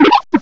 sovereignx/sound/direct_sound_samples/cries/watchog.aif at 2f4dc1996ca5afdc9a8581b47a81b8aed510c3a8